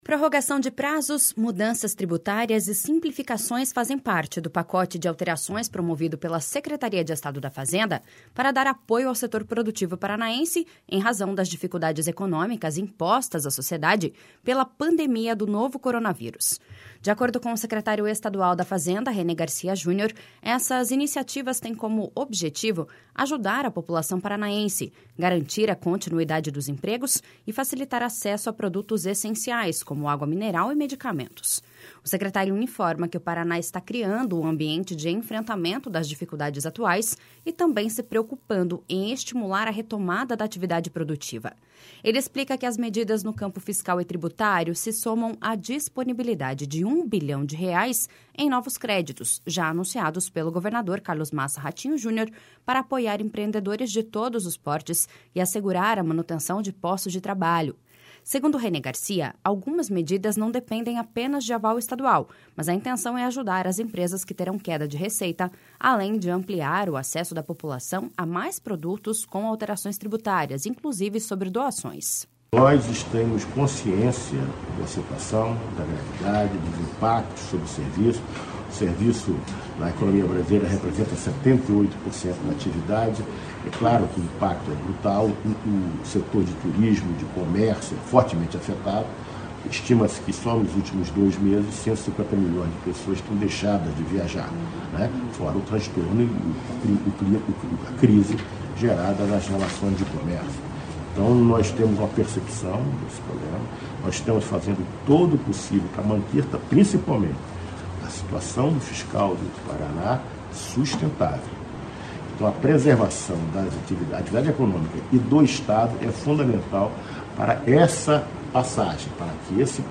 // SONORA RENÊ GARCIA JUNIOR.//